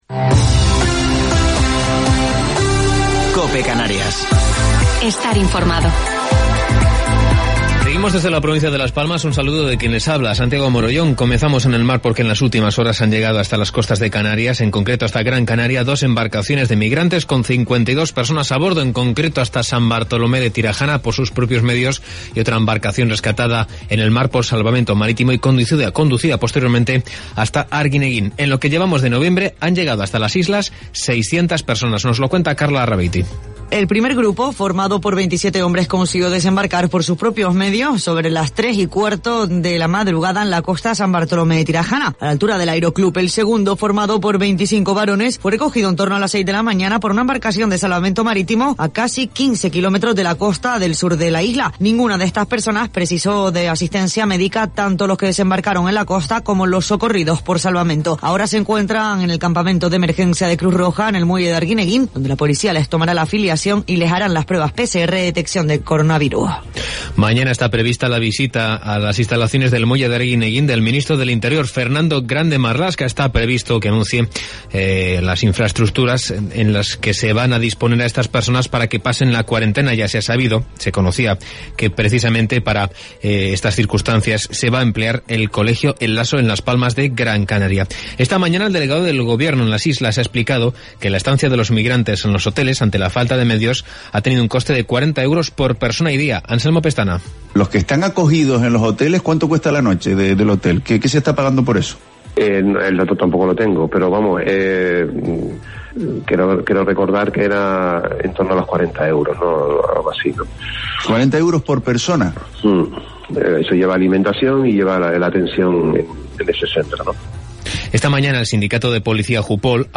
Informativo local 5 de Noviembre del 2020